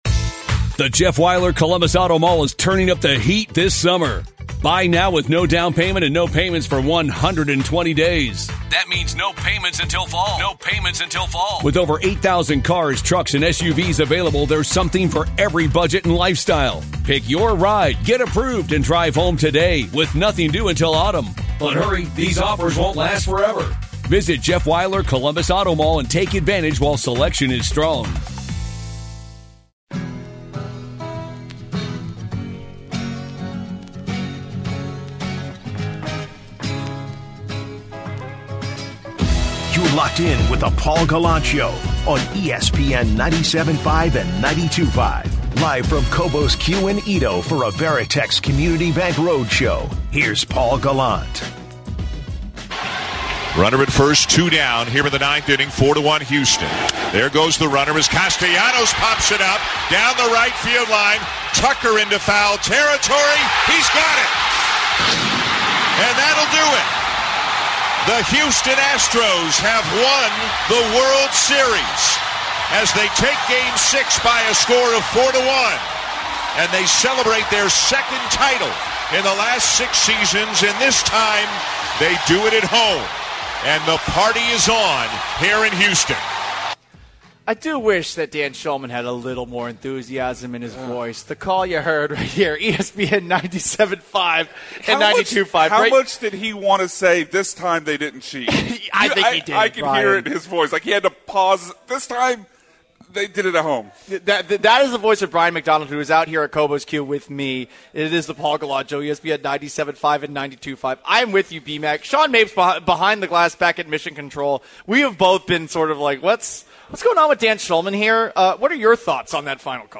MORE ASTROS WORLD SERIES REACTION LIVE FROM COBOS